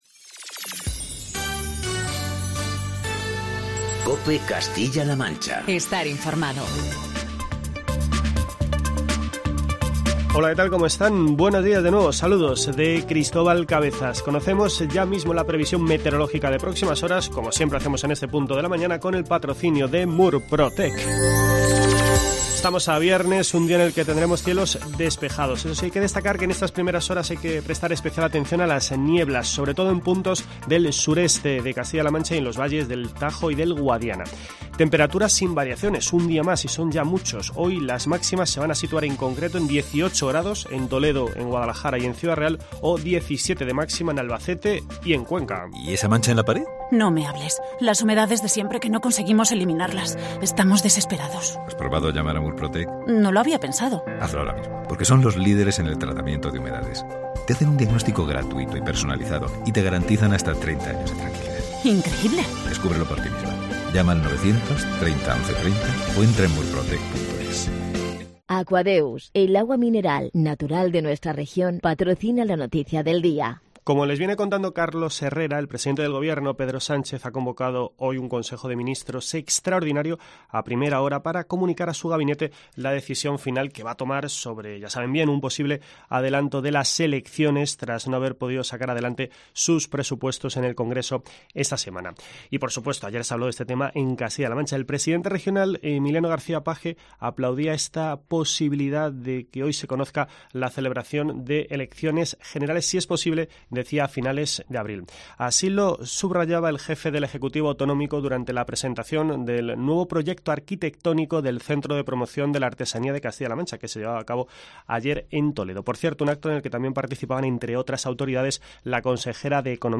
Informativo matinal de COPE Castilla-La Mancha